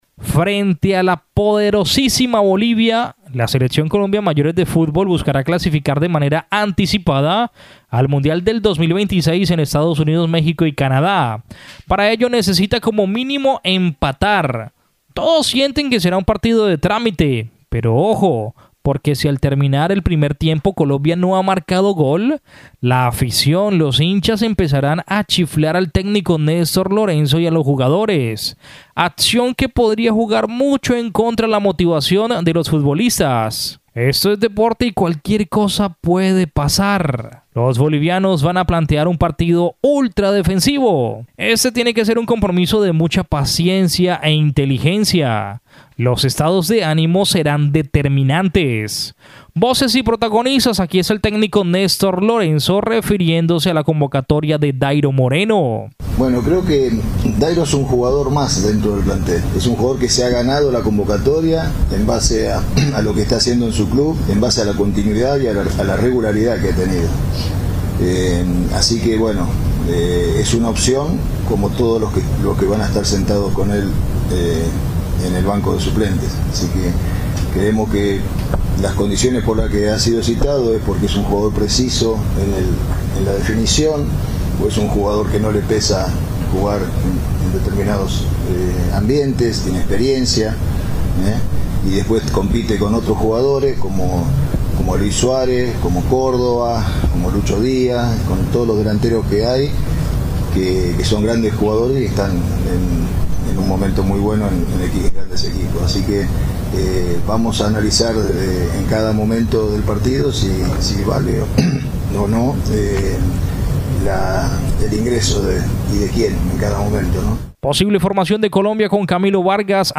Voces y protagonistas, aquí está el técnico Néstor Lorenzo, refiriendose a la convocatoria de Dayro Moreno.